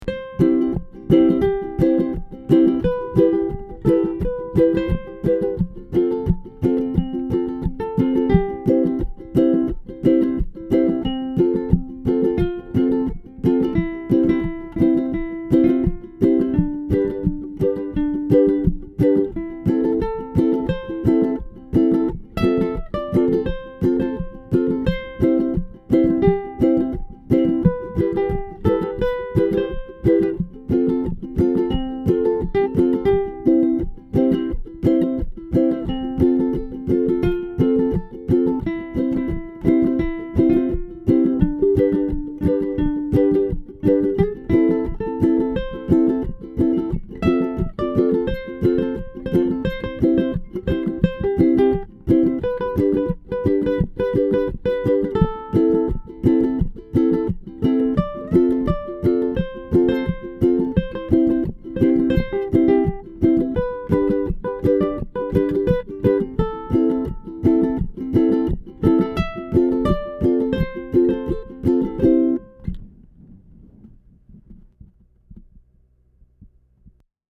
Ну и гавайская классика (дальше подбирать впадлу)